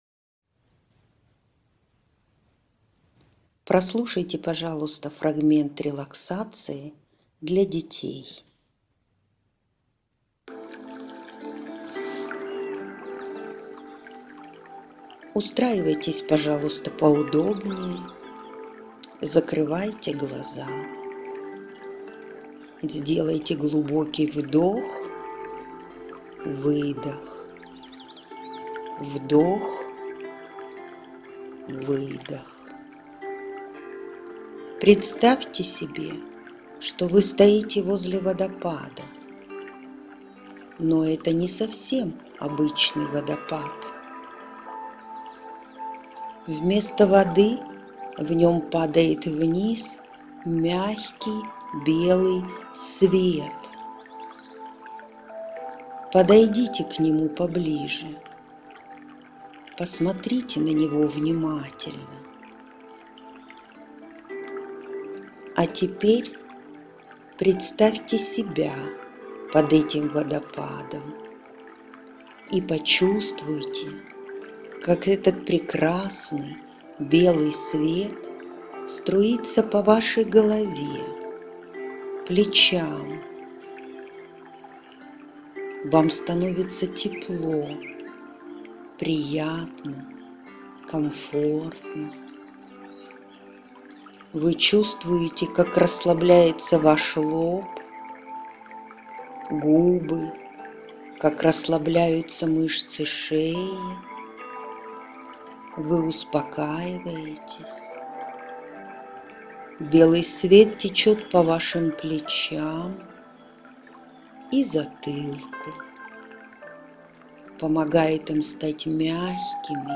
滝の子供のためのリラクゼーション 無料ダウンロードとオンライン視聴はvoicebot.suで